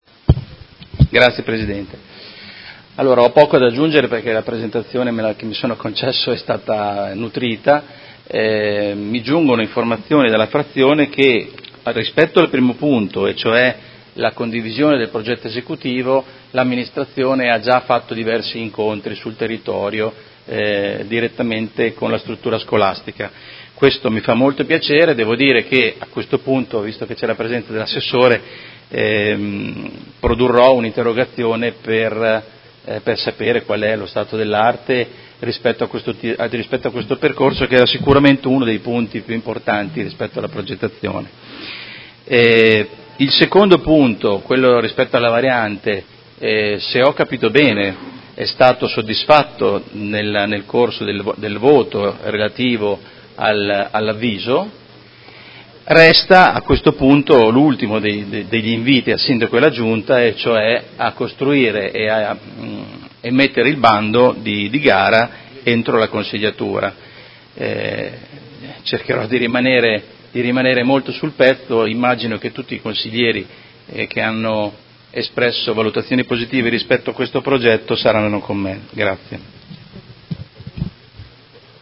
Seduta del 10/01/2019 Dibattito.